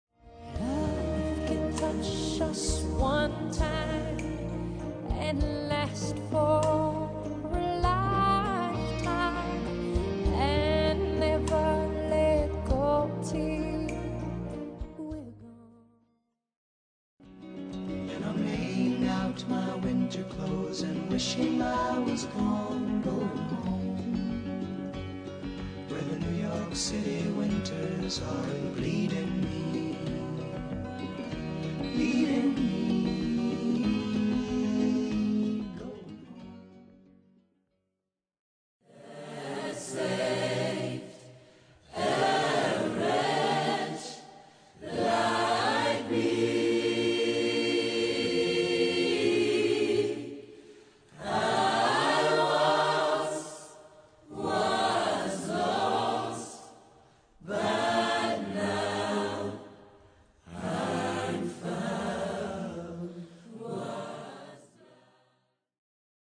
Questo esempio audio presenta una successione di tre brevi frammenti melodici, tratti dalla musica moderna.
Il primo ad una voce; il secondo a due voci; il terzo a più voci.
monodia_polifonia.mp3